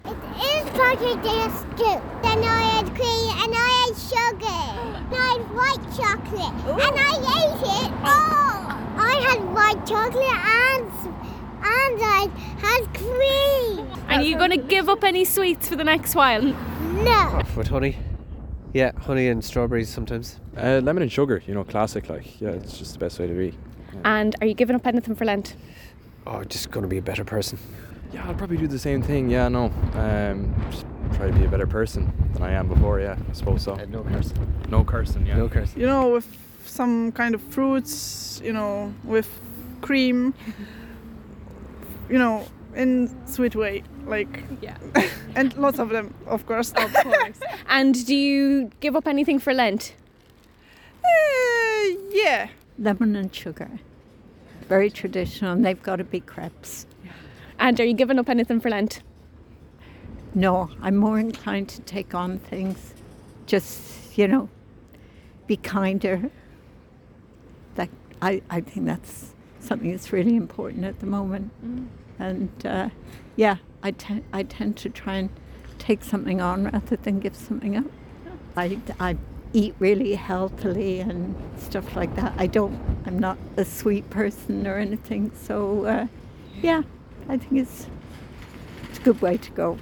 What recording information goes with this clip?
This article first appeared on Galway Bay FM